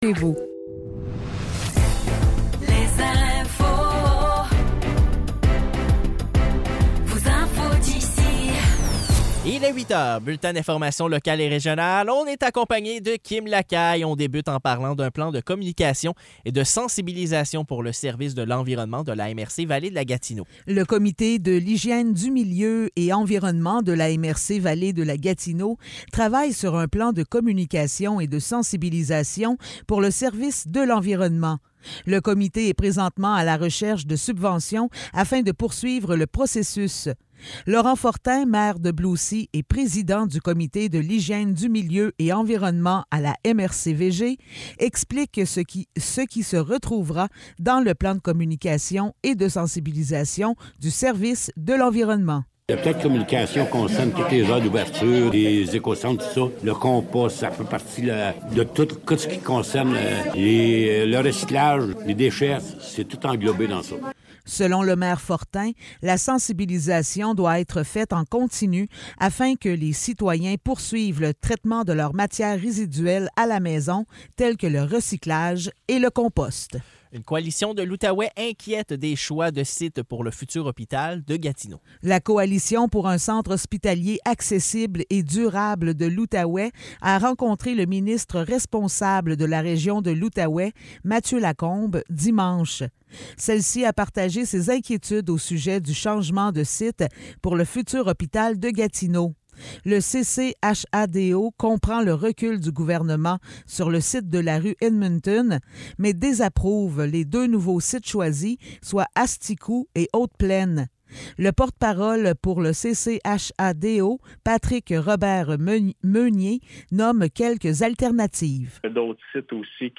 Nouvelles locales - 26 octobre 2023 - 8 h